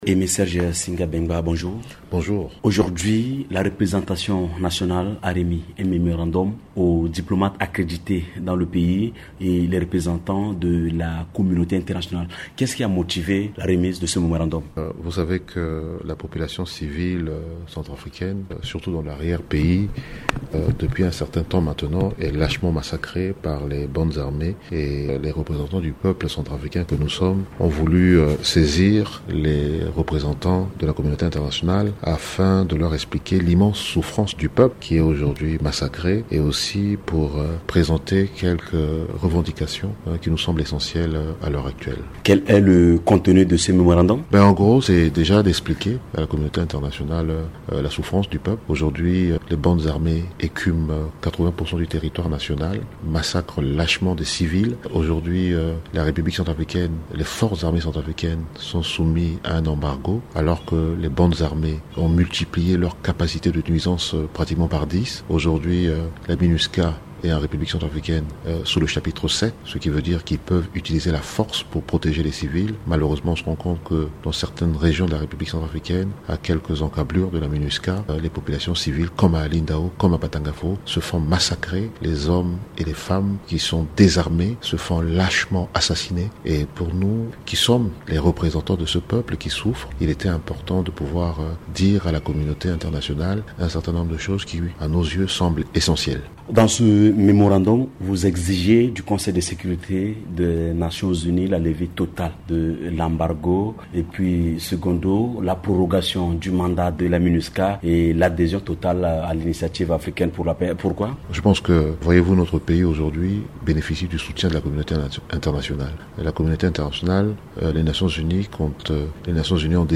Le député de Bangassou, Aimé Serge Singha Béngba, explique que l’objet de ce mémorandum est de faire toucher du doigt aux diplomates accrédités en Centrafrique le degré de souffrance des filles et fils de ce pays. Pour Aimé Serge Singha Béngba, il est question de présenter aussi des revendications sur la manière dont sont gérés les différents contingents de la Minusca.